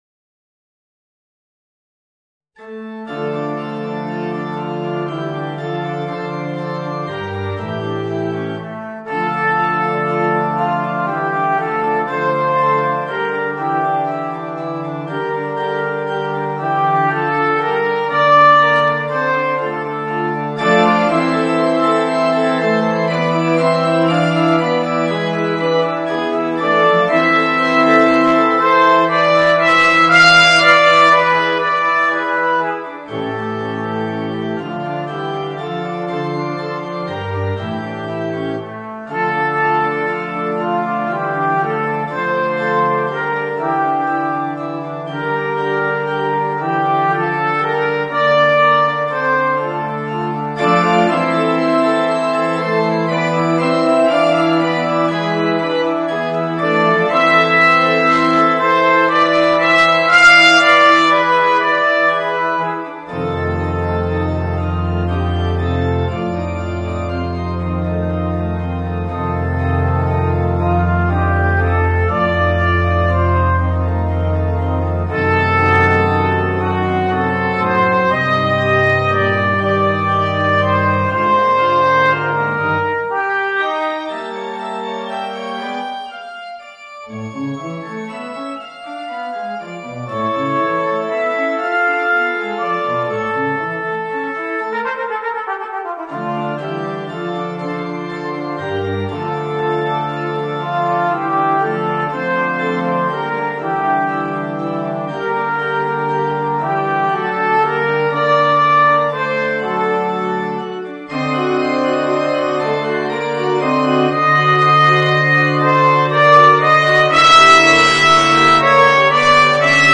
Voicing: Trumpet and Organ